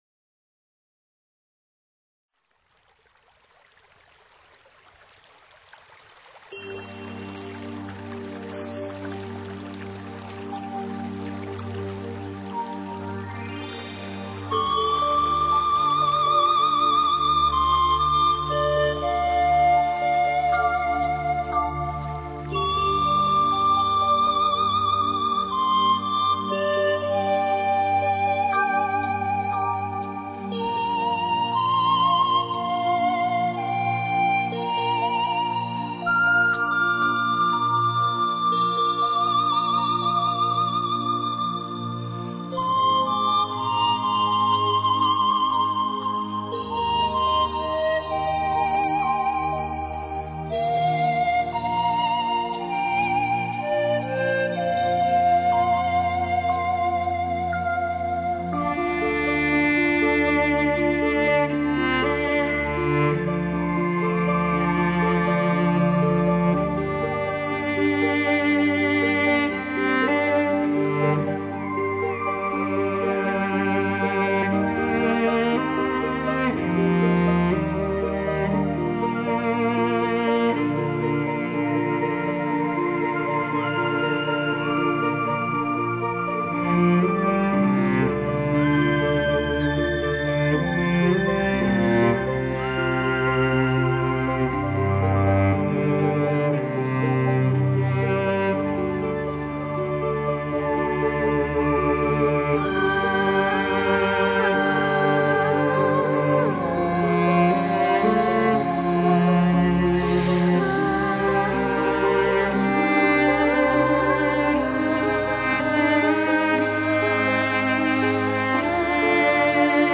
light]民乐
美丽的旋律和吟 唱,这自然的乐音，悠长而深邃，没有界限地与人类灵魂的对话。